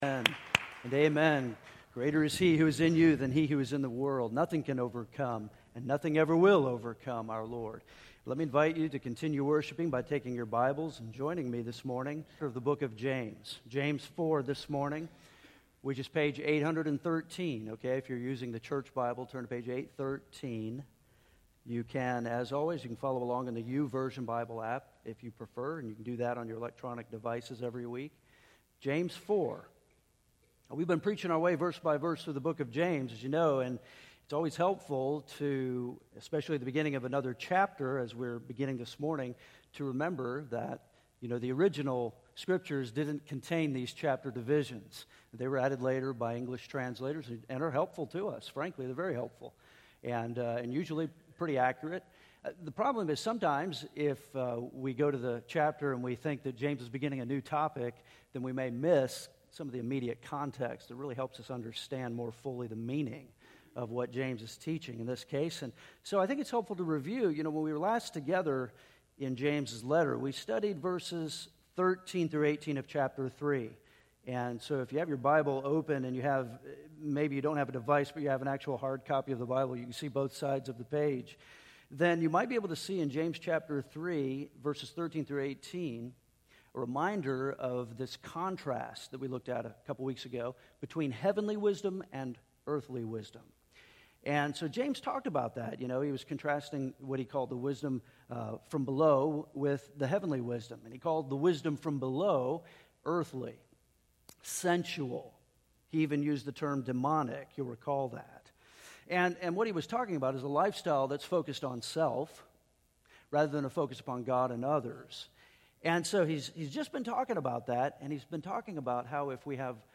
We have been preaching our way verse-by-verse through the book of James and we begin chapter 4 this morning.